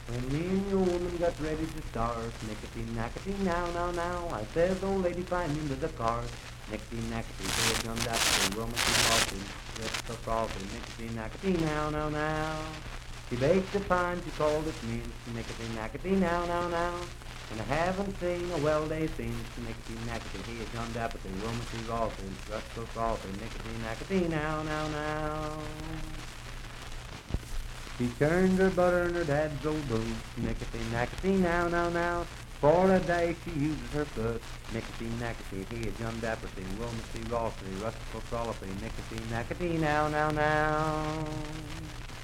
Voice (sung)